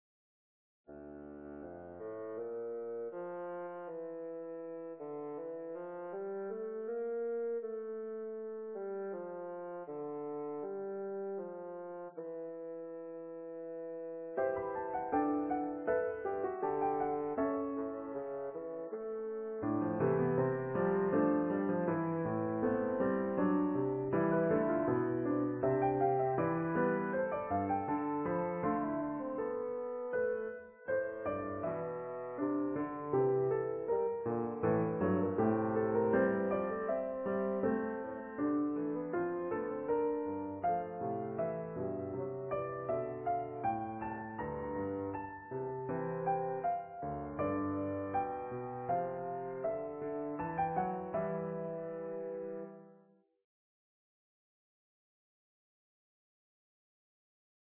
Composition for Bassoon and Piano
This piece is really intended for cello and piano. But the Sibelius robot’s cello sound is unbearable, hence the instrumentation change.